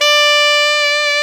SAX ALTOMF04.wav